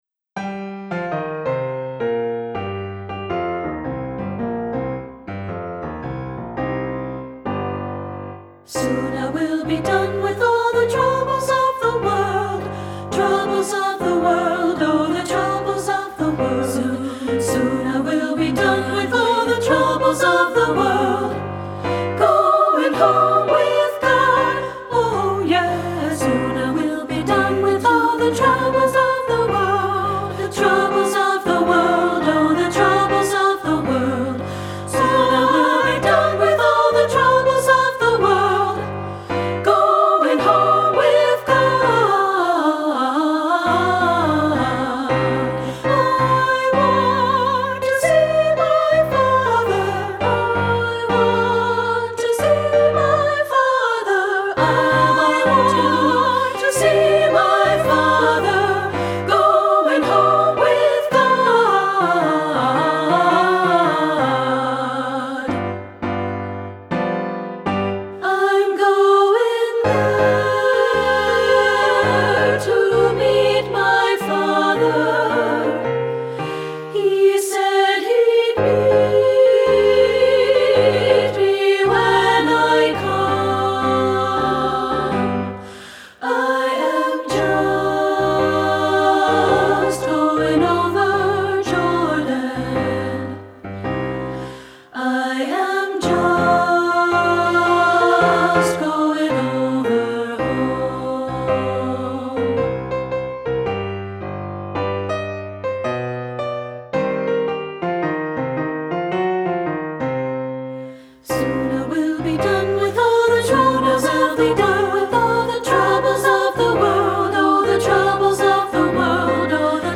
• Soprano
• Alto
• Piano
Studio Recording
In this compelling SA arrangement
Ensemble: Treble Chorus
Accompanied: Accompanied Chorus